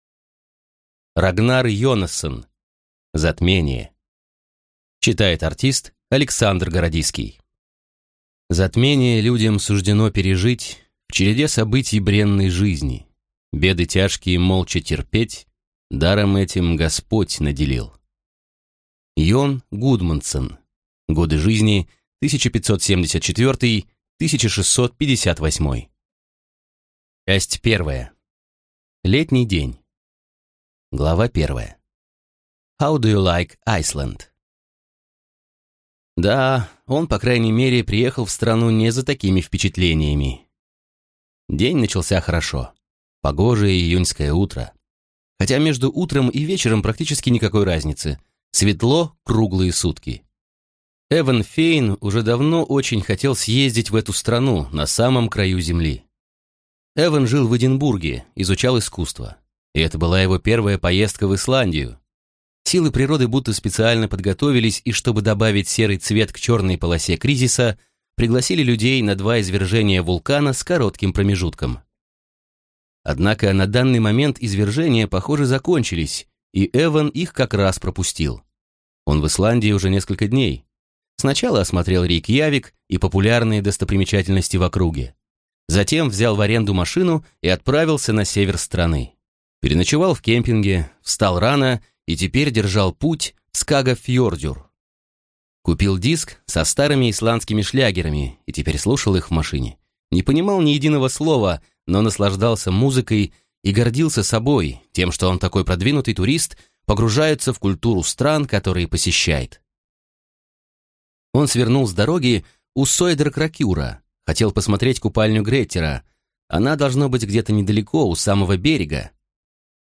Аудиокнига Затмение | Библиотека аудиокниг